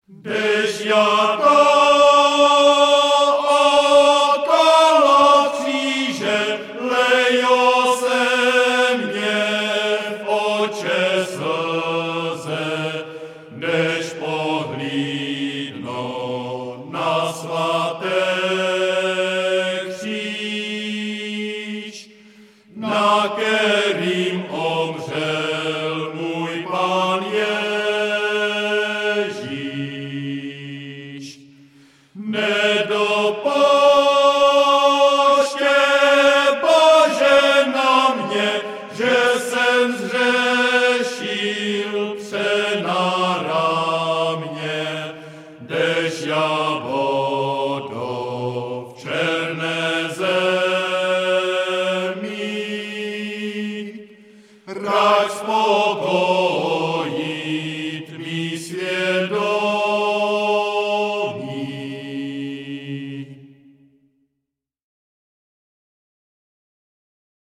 Žánr: World music/Ethno/Folk
písní a capella